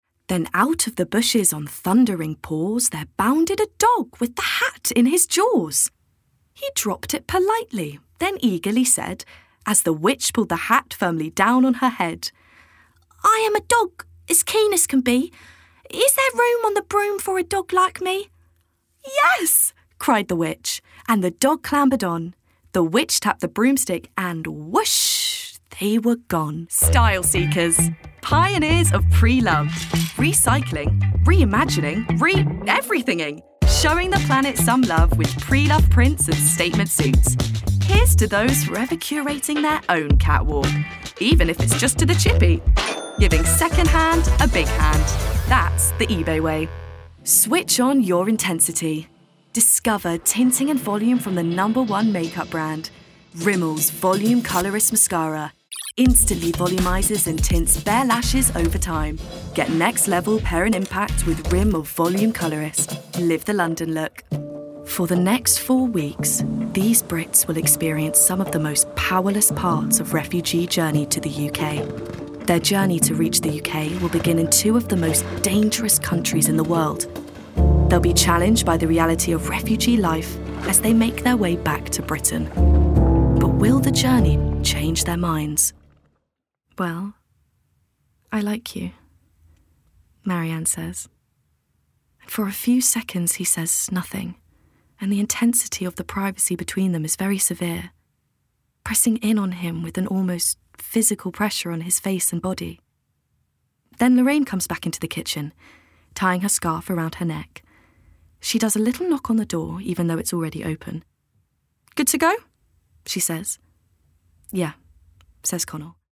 RP
Voicereel:
BRITISH ISLES: Heightened RP, West Country, Essex, Scouse, Yorkshire, Welsh, MLE
GLOBAL: Australian, American-Southern States, Standard-American